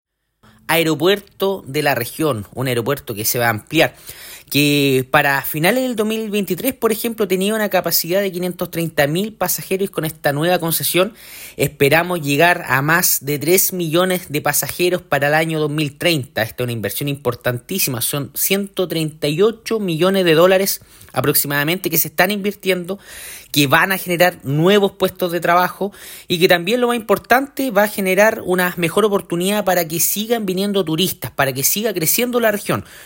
La información la confirmó el seremi del Ministerio de Obras Pública de La Araucanía, Patricio Poza, quien dijo que esto será un impulso para la actividad turística.